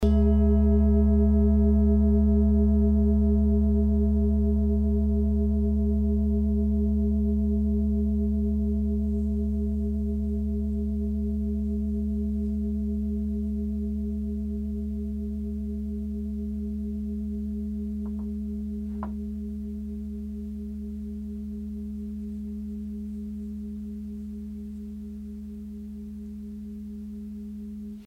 Klangschale Nepal Nr.55
Klangschale-Gewicht: 2020g
Klangschale-Durchmesser: 29,4cm
klangschale-nepal-55.mp3